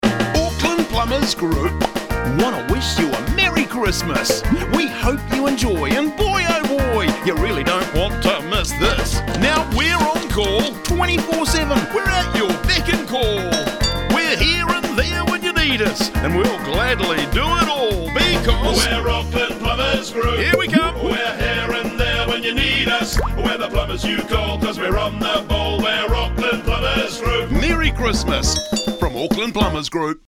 Radio-advert-Christmas-1225.mp3